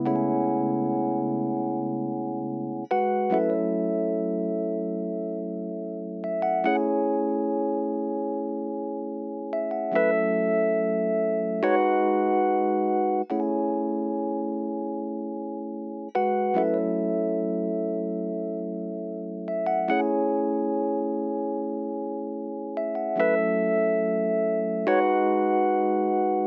02 rhodes A2.wav